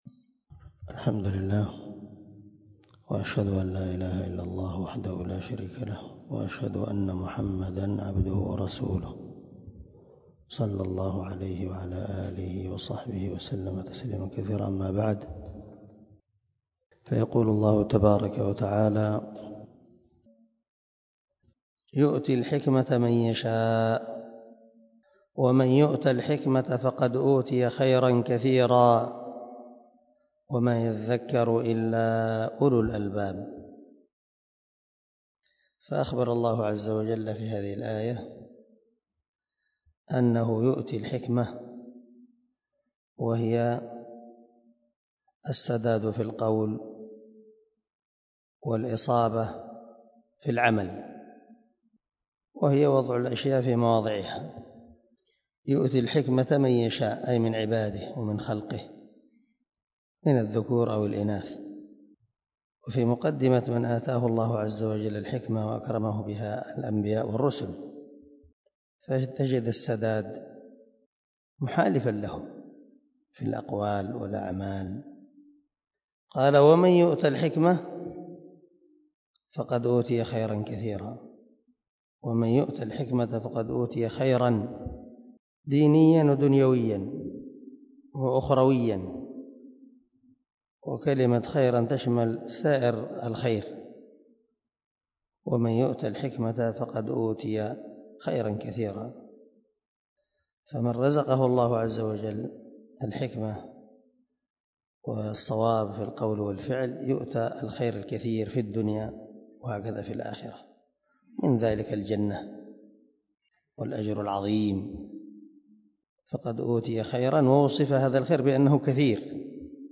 144الدرس 134 تفسير آية ( 269 ) من سورة البقرة من تفسير القران الكريم مع قراءة لتفسير السعدي
دار الحديث- المَحاوِلة- الصبيحة